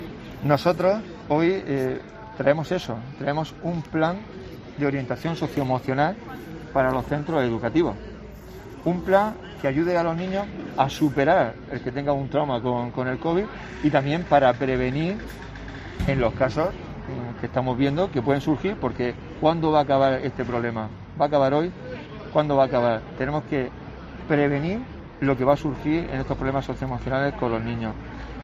Pedro Mondéjar, edil del PP sobre Plan de Orientación Municipal